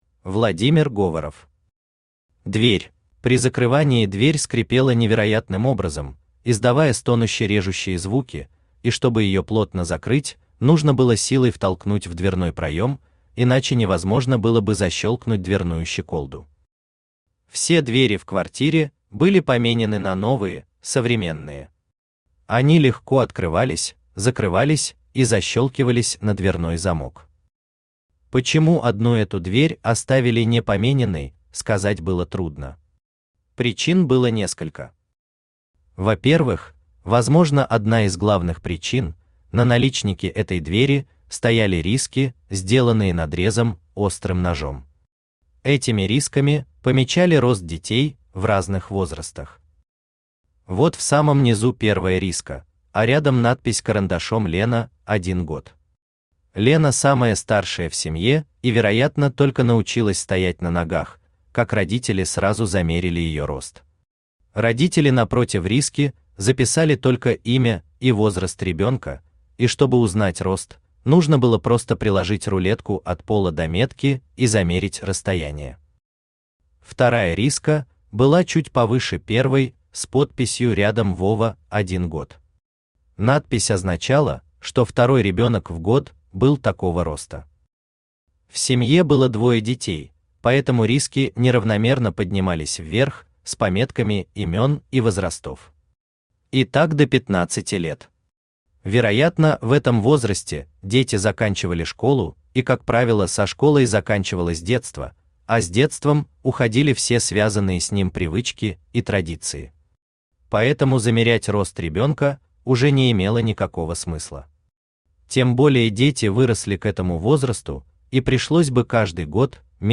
Aудиокнига Дверь Автор Владимир Говоров Читает аудиокнигу Авточтец ЛитРес.